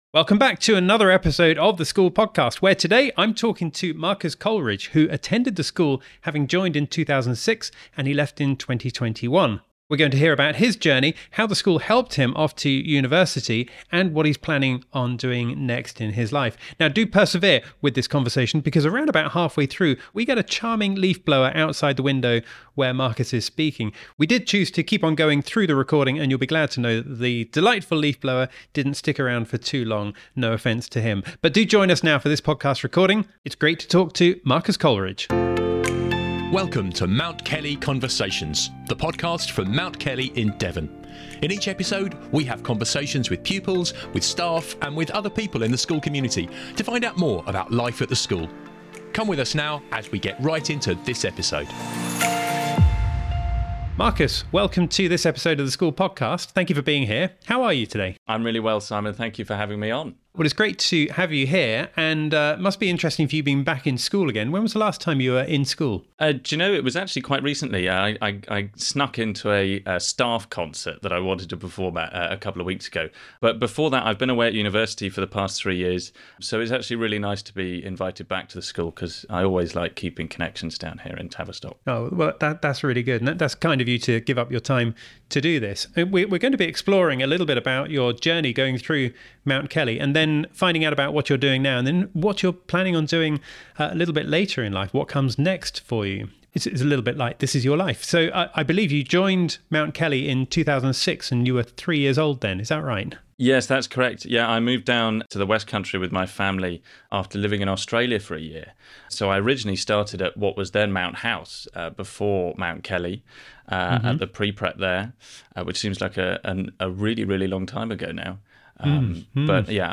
In conversation with former pupil